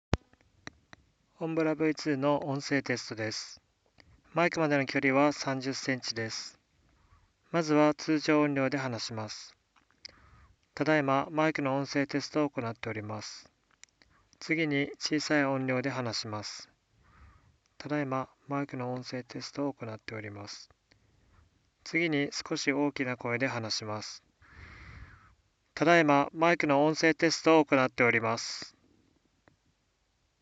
ヘッドセットのマイクが拾った音声は比較的クリアで、ゲーム内のコミュニケーションで支障が出ることは無いと思われます。
■検証③（OMBRA v2を装着＆ヘッドセットを装着して読み上げる）
※「マイクまでの距離は30cm」と言っていますが、検証③ではヘッドセットのマイクなので数cmです。
OMBRA_v2_インナーマイク装着.mp3